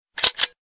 lomo_click.mp3